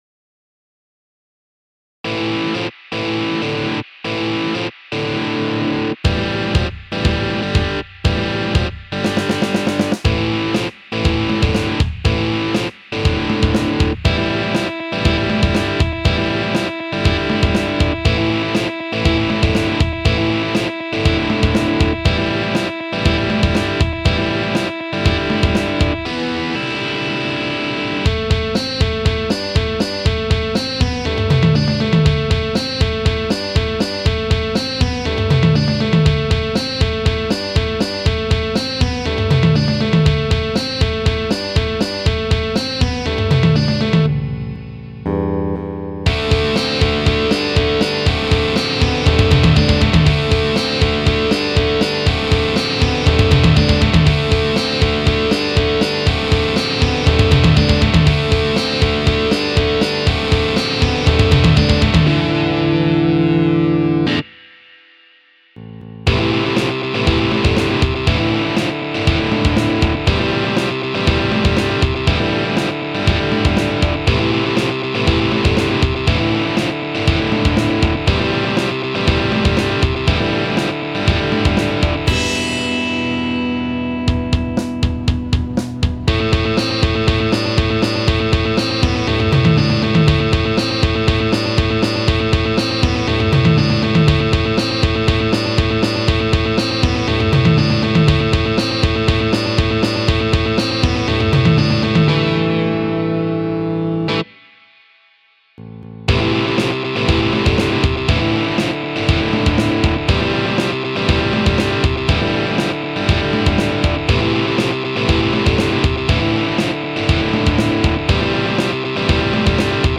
Моя версія відомої пісні... без слів, звичайно. Лише музика.